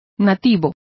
Also find out how nativo is pronounced correctly.